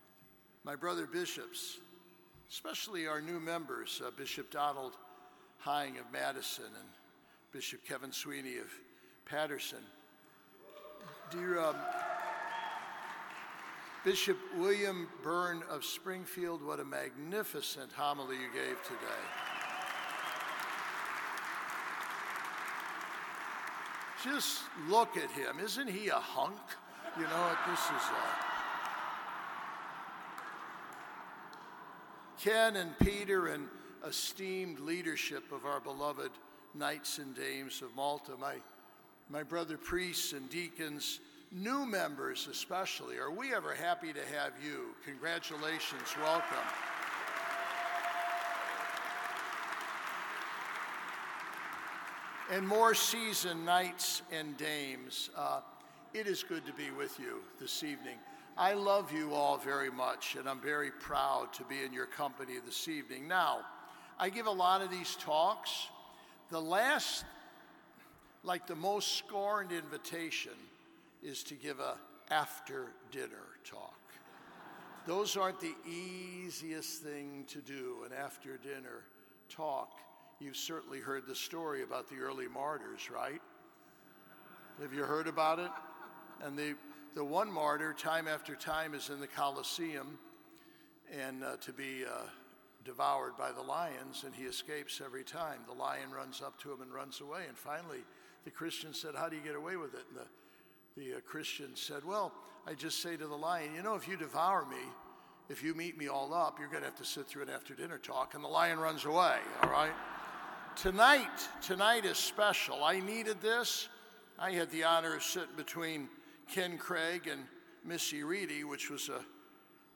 Cardinal-Timothy-Dolan-Talk-2024-Annual-Dinner_.mp3